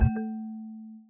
foul.wav